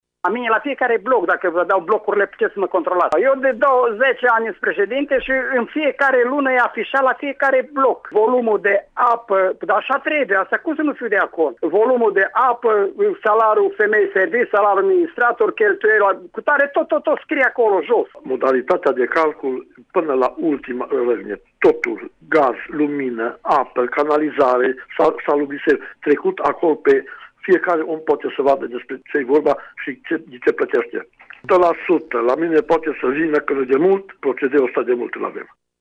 Preşedinţii a două asociaţii de proprietari din Tg.-Mureş cunosc noua reglementare, dar spun că oricum aplicau această metodă, chiar dacă nu exista o prevedere legislativă expresă: